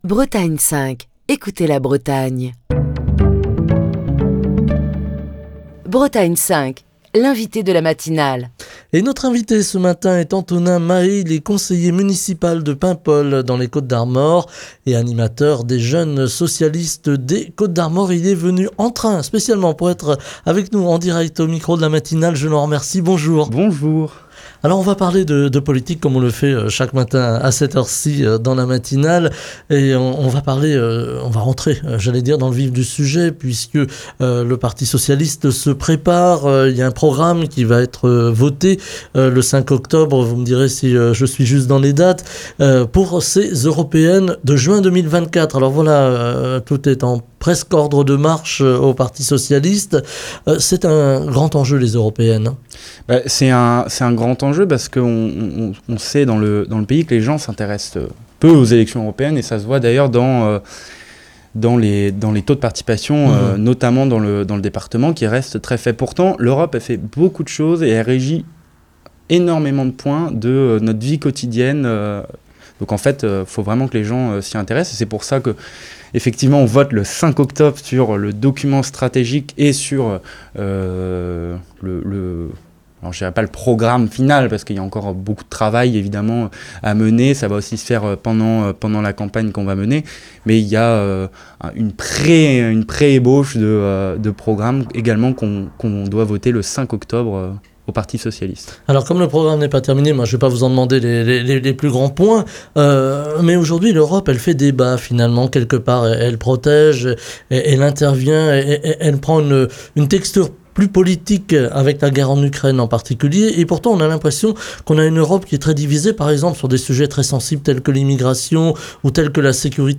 Émission du 14 septembre 2023. Les fédérations socialistes de Bretagne organisent leur université de rentrée à Morlaix les 29 et 30 septembre.
Nous en parlons ce matin avec Antonin Mahé, conseiller municipal de Paimpol, animateur des Jeunes Socialistes des Côtes d'Armor qui est l'invité politique de la matinale. Écouter Télécharger Partager le podcast Facebook Twitter Linkedin Mail L'invité de Bretagne 5 Matin